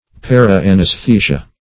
Search Result for " para-anaesthesia" : The Collaborative International Dictionary of English v.0.48: Para-anaesthesia \Par`a-an`aes*the"si*a\, Par-anesthesia \Par`-an`es*the"si*a\, n. [NL.; para- + an[ae]sthesia.]
para-anaesthesia.mp3